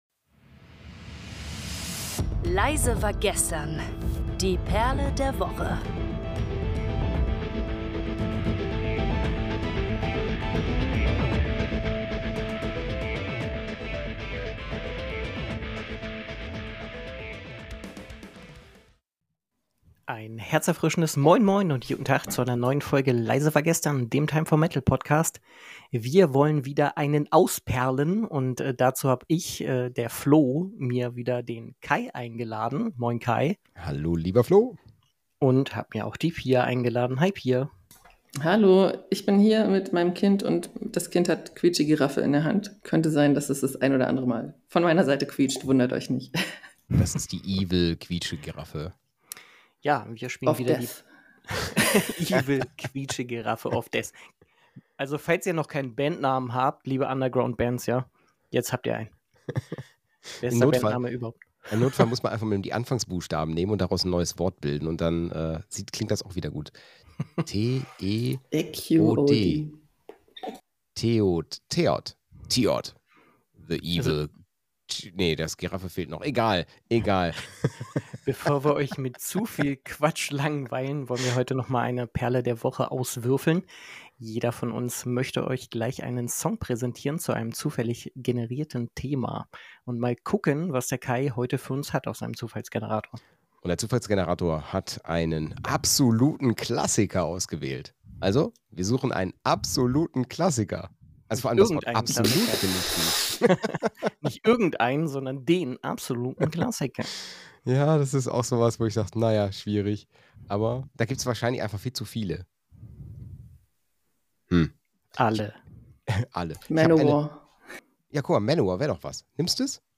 Die Diskussion ist geprägt von einer humorvollen und lockeren Atmosphäre, die zeigt, dass Metal nicht nur ein Musikgenre ist, sondern eine starke emotionale Bindung schaffen kann.